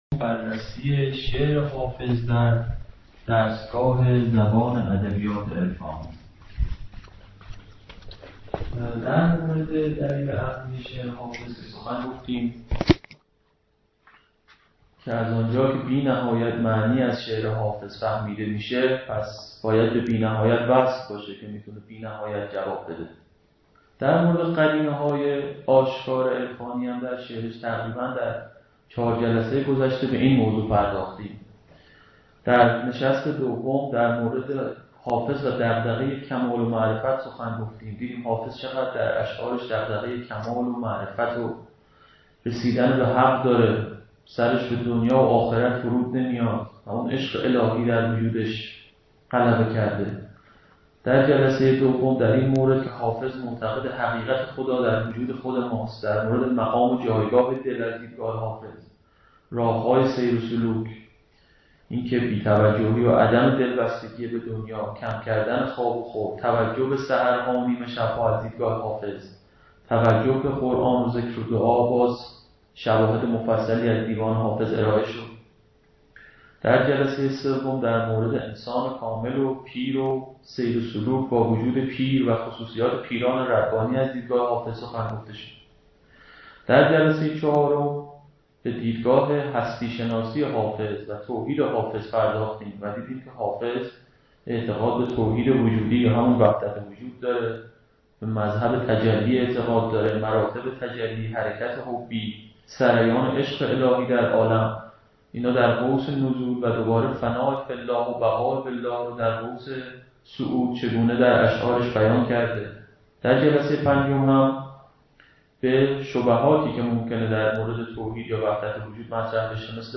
ششمین و آخرین جلسه عرفان حافظ در دانشگاه یزد برگزار شد